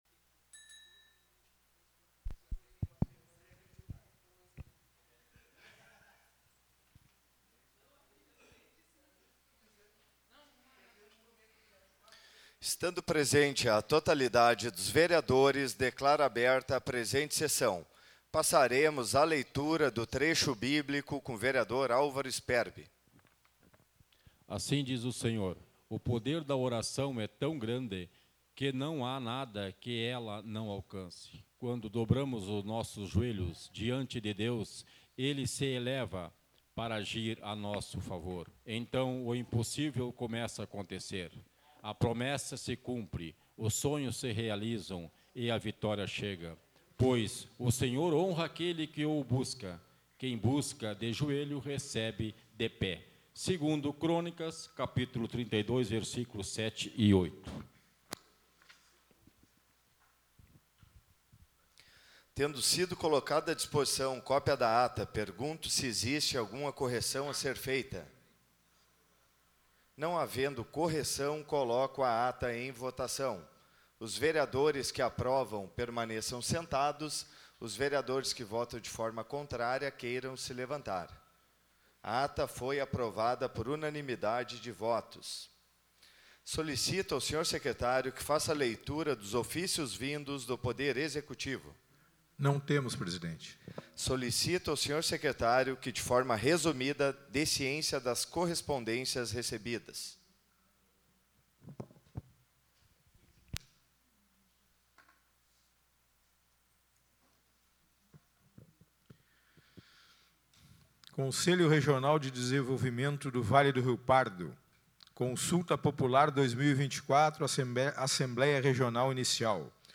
Áudio Sessão 14.10.2024 — Câmara de Vereadores